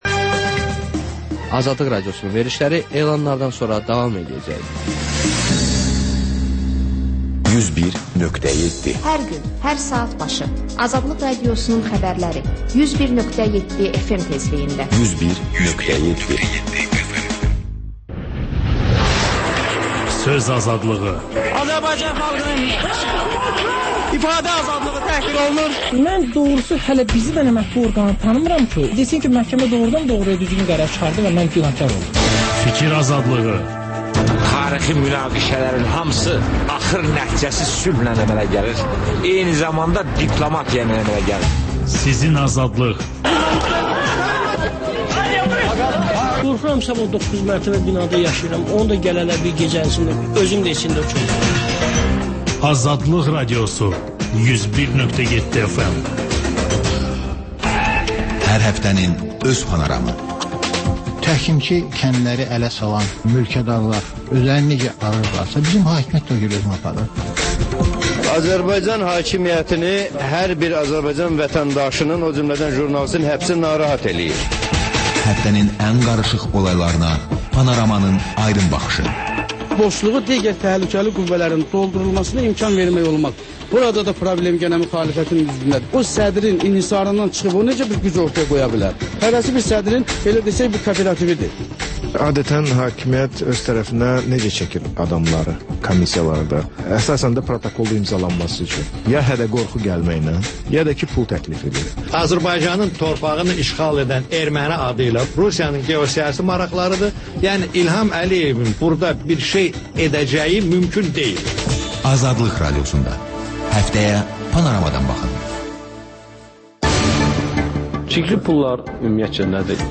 Xəbərlər, müsahibələr, hadisələrin müzakirəsi, təhlillər, sonda HƏMYERLİ rubrikası: Xaricdə yaşayan azərbaycanlılar haqda veriliş